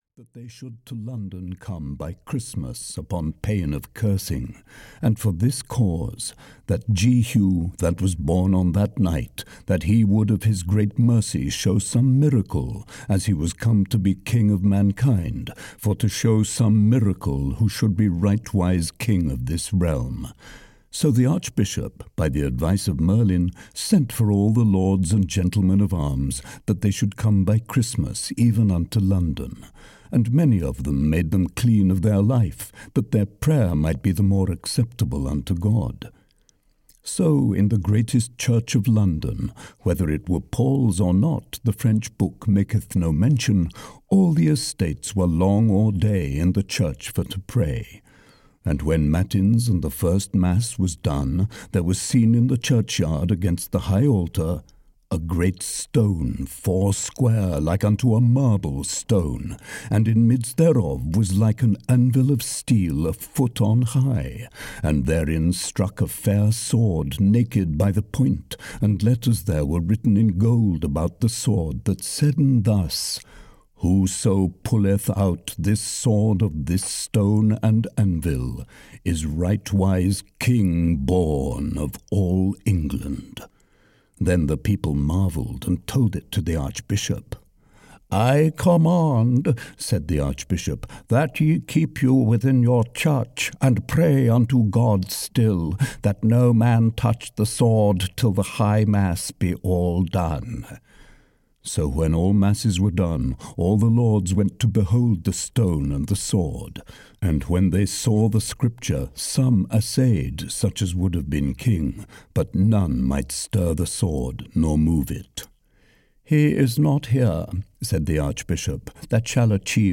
Le Morte d'Arthur (EN) audiokniha
Ukázka z knihy